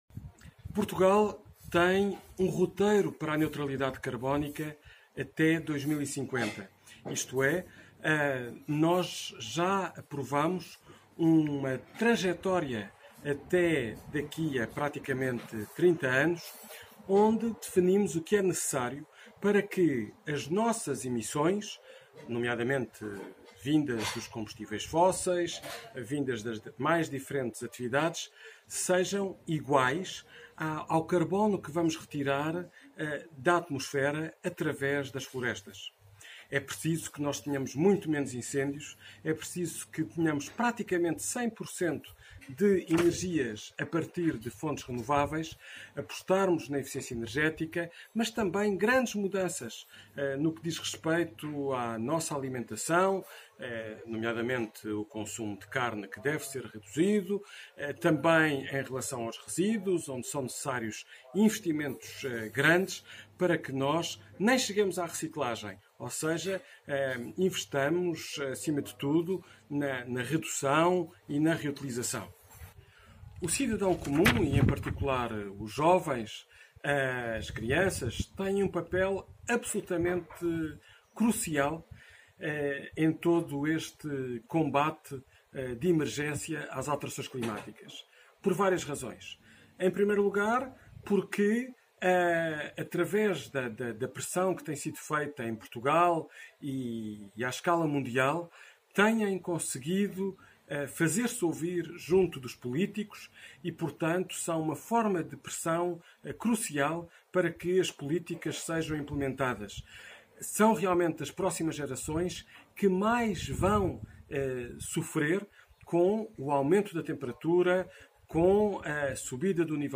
um discurso que pode ser ouvido aqui.